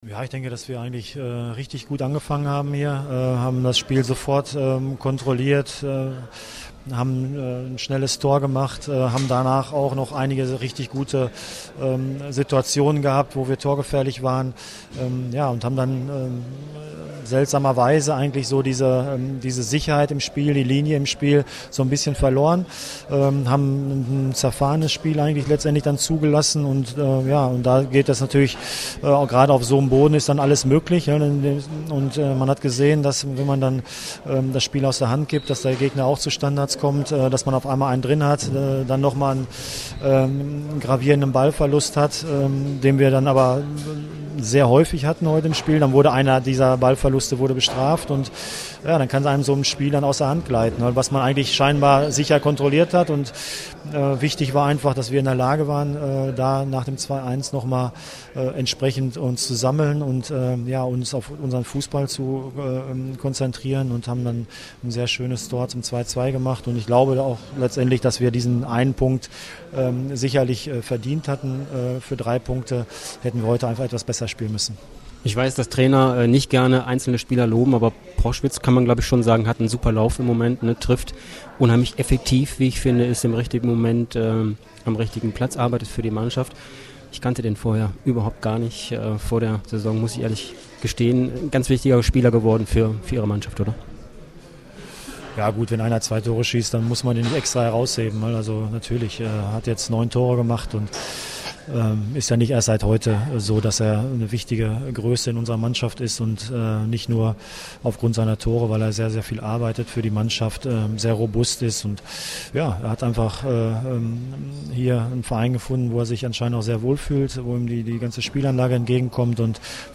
Audiokommentar
Chef-Trainer Roger Schmidt zum Spiel
FSV-SCP_Schmidt.mp3